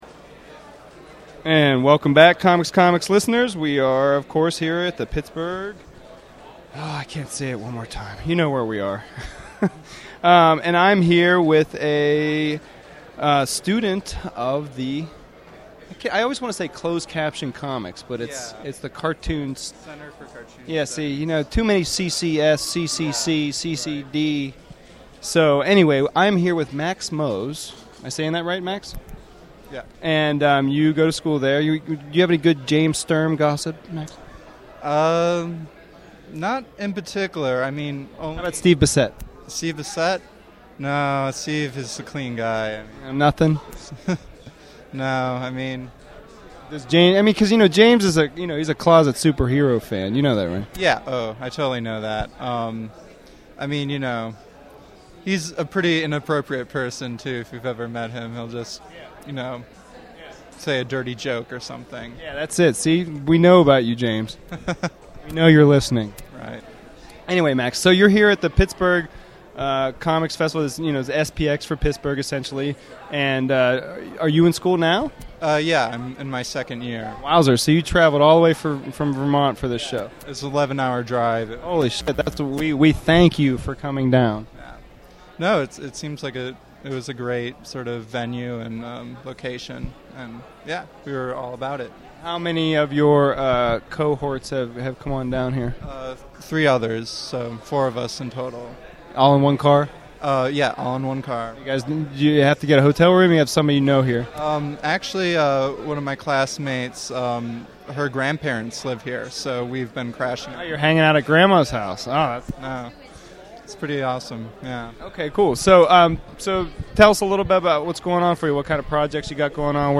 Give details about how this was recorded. This week I am presenting a slew of interviews I conducted with a plethora of cartoonists who exhibited at this year’s Pittsburgh Indy Comics Expo.